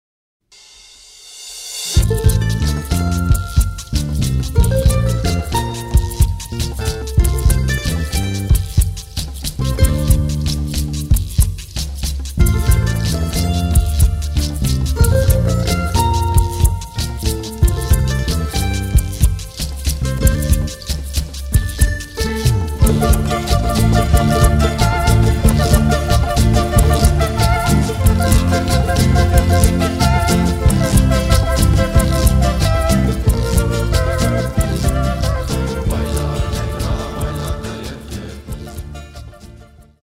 A recording with the BEST TRADITIONAL music of the Andes.